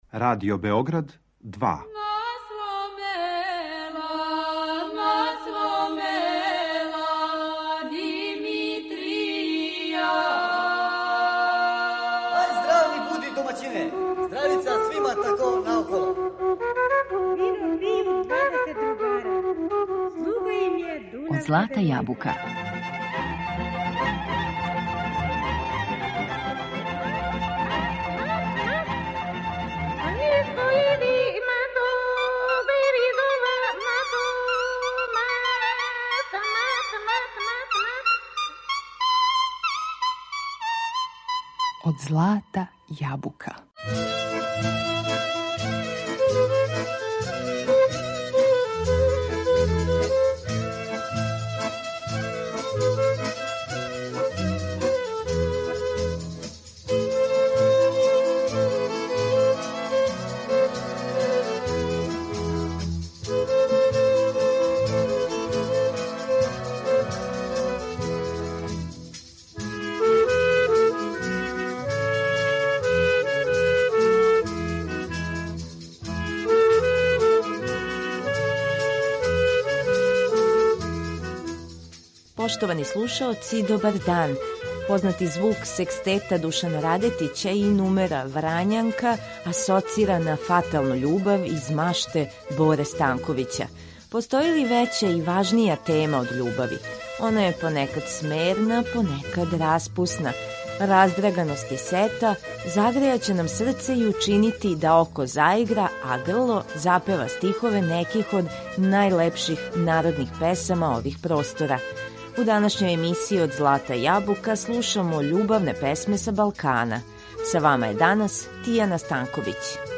Раздраганост и сета истовремено, управо су оно што ће учинити да нам око заигра, уз најлепше мелодије ових простора. У данашњем издању емисије Од злата јабука на репертоару су најлепше песме са Балкана.